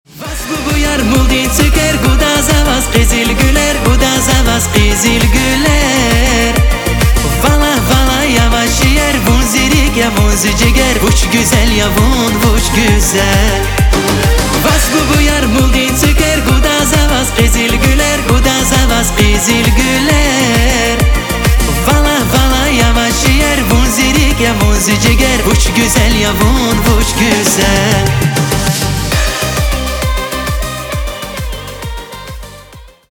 восточные на девушку